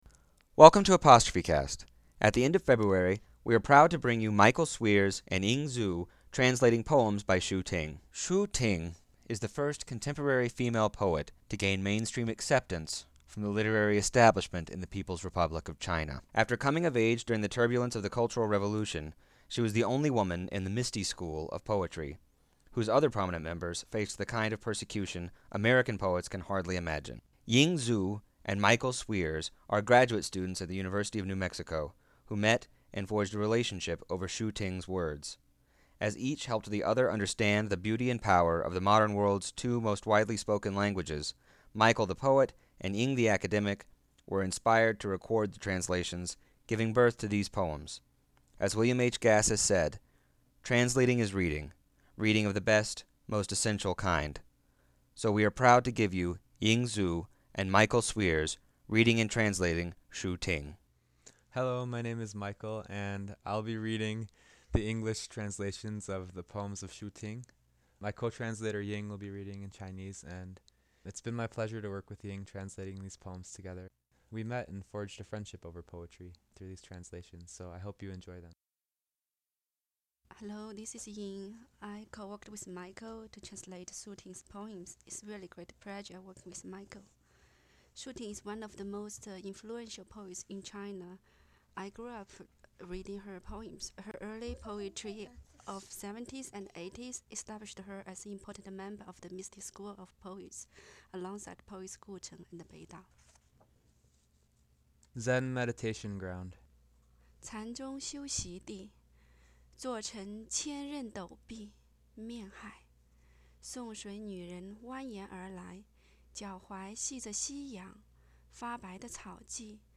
Chinese poetry